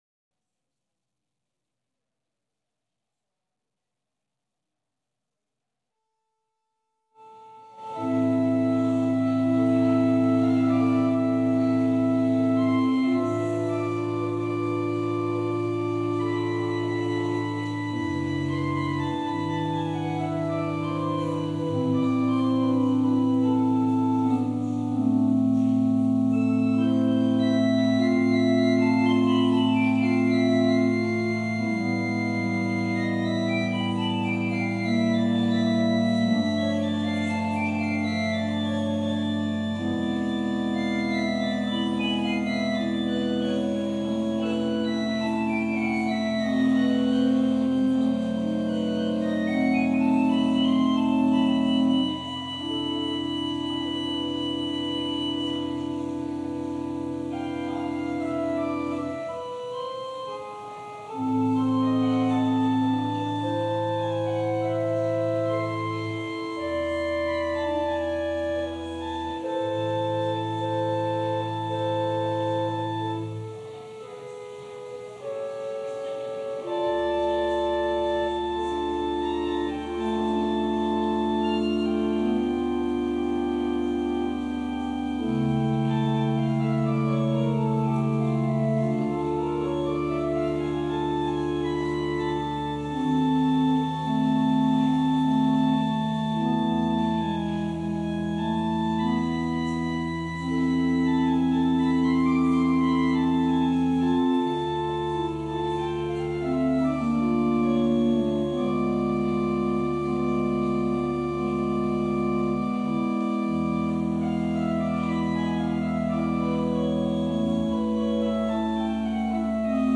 Passage: Luke 18: 9-14 Service Type: Sunday Service Scriptures and sermon from St. John’s Presbyterian Church on Sunday